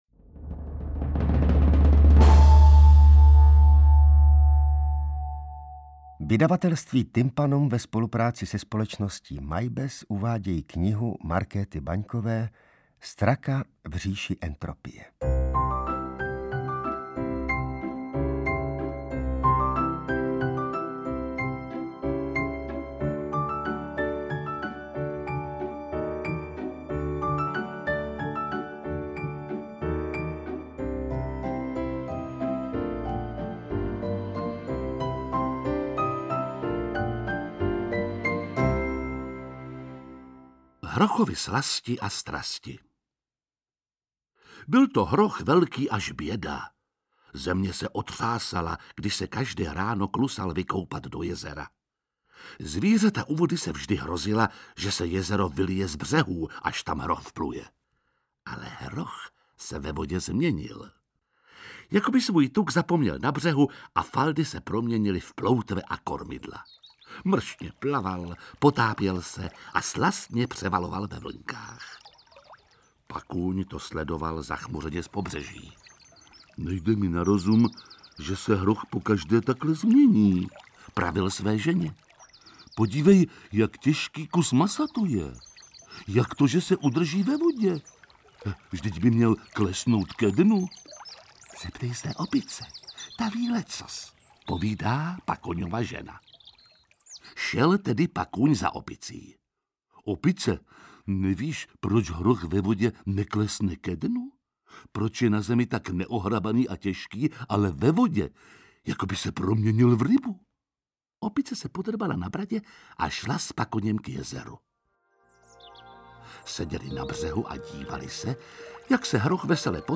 Interpreti:  Arnošt Goldflam, Barbora Hrzánová, Jiří Lábus, Libuše Šafránková, Libuše Švormová, Ivan Trojan
Kniha bajek pro dospělé i děti tentokrát ve formátu MP3. Zvířata v nich luští záhady existence a fungování světa. Audiokniha humornou formou přibližuje klasické fyzikální zákony, které pochopí i malé děti.
1. Hrochovy slasti a strasti (Archimedův zákon) čte Jiří Lábus